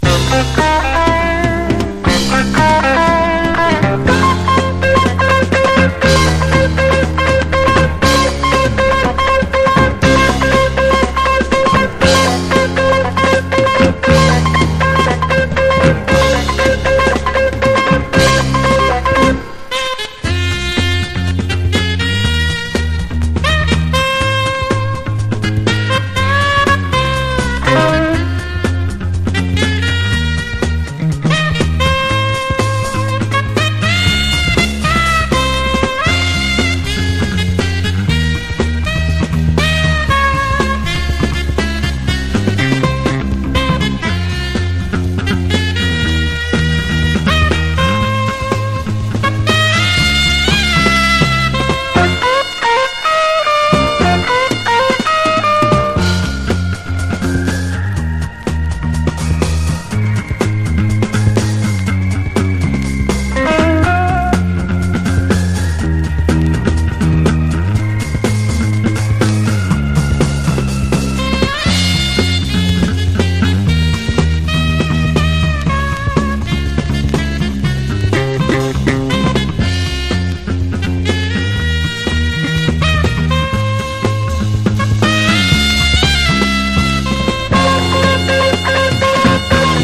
和モノブレイク / サンプリング
ポピュラー# SOUNDTRACK / MONDO
薄スレ等が少しあるが、リスニングに問題なし。